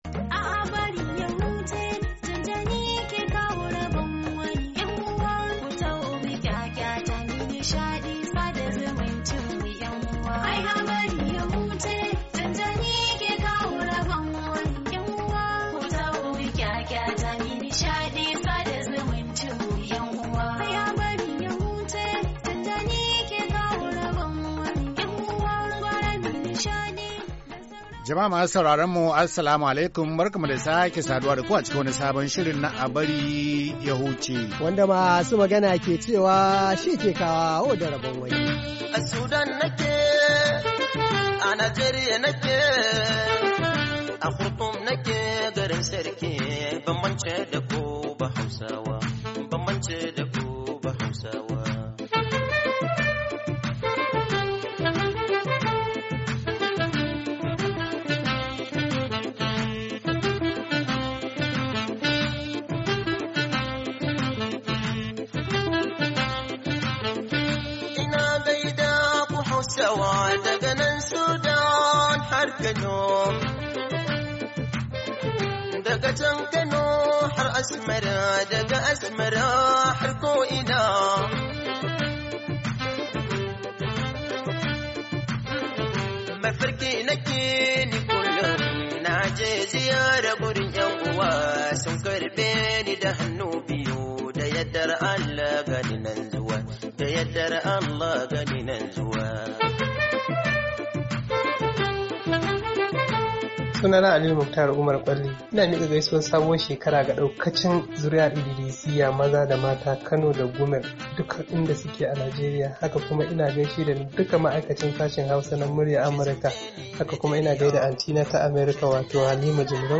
Saurari shirinmu na "A Bari Ya Huce..." na ranar Asabar, 30 Disamba 2017, domin jin labaran ban dariya na wannan mako, da kuma irin kade-kade da gaishe-gaishen da suka samu shiga cikin shirin.